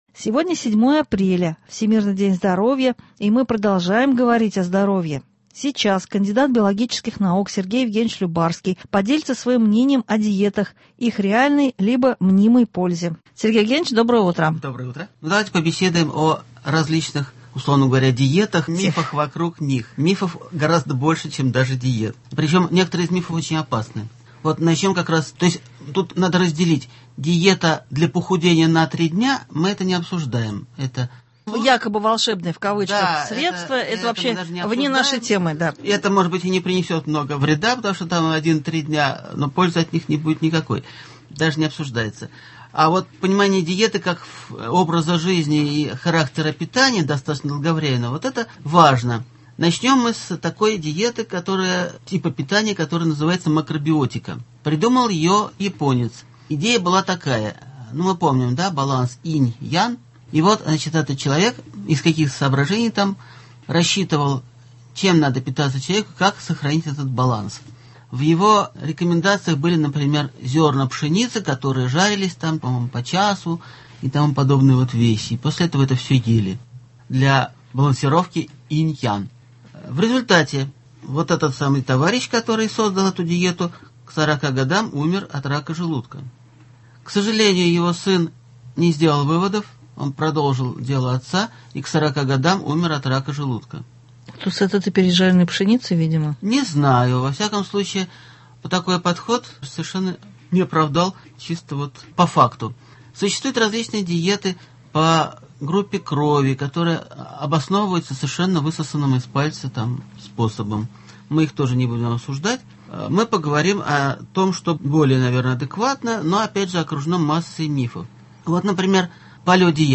Сегодня Всемирный день здоровья. Сегодня беседуем о диетах.